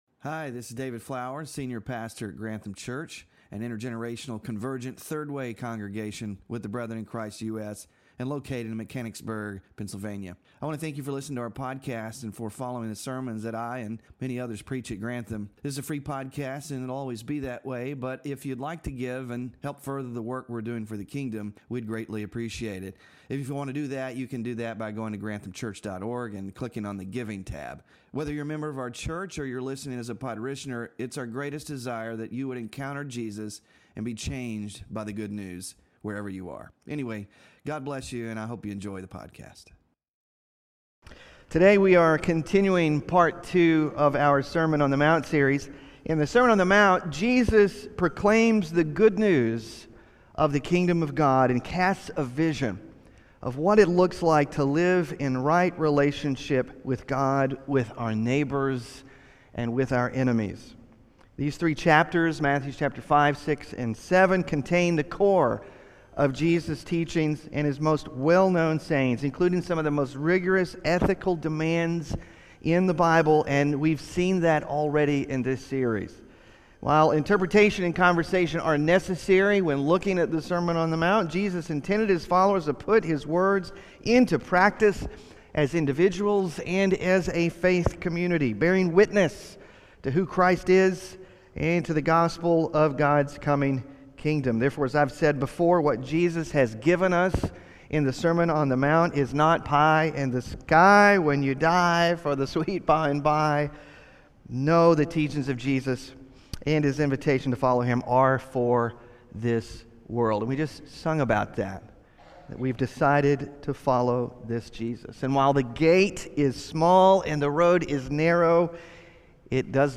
Sermon Focus: In the next section of the Sermon on the Mount, Jesus calls us to trust God as a good Father who invites us to ask, seek, and knock—to be intentional in asking God for wisdom in prayer as we seek to be in right relationship with others.